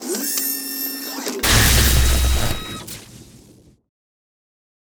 grasp.wav